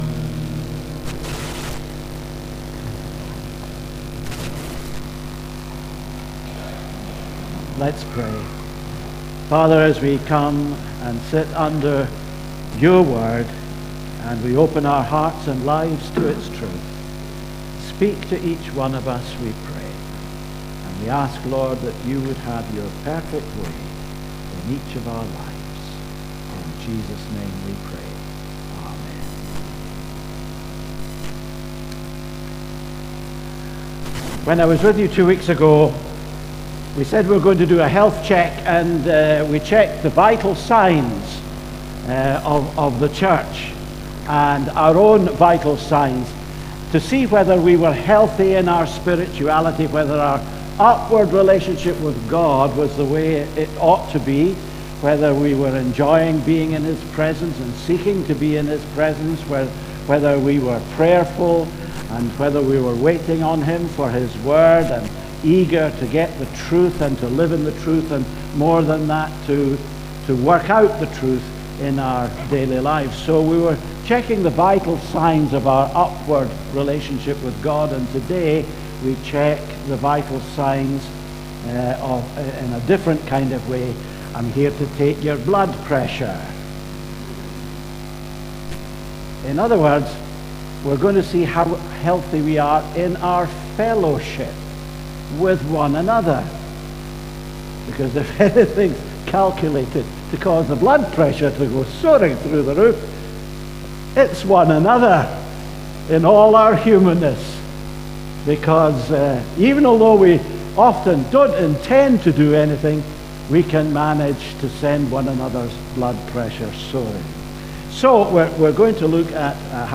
Sermons (Audio) - Dedridge Baptist Church